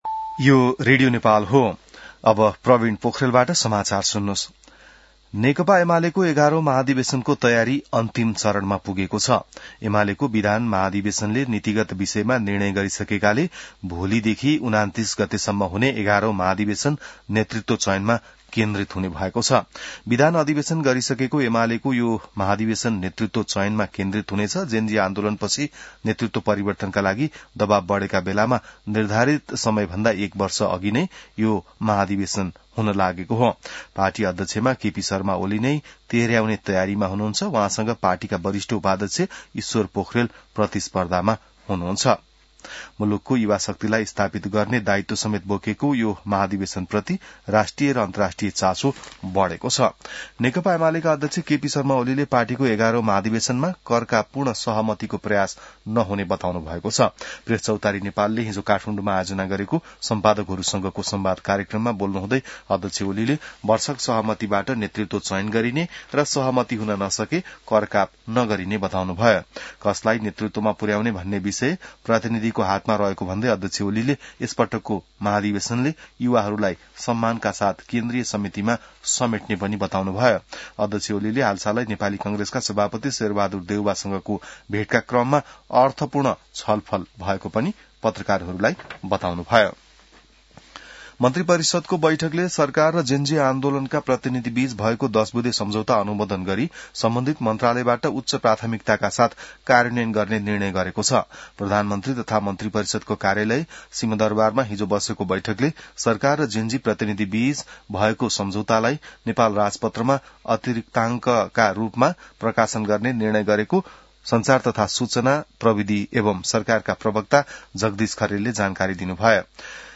बिहान ६ बजेको नेपाली समाचार : २६ मंसिर , २०८२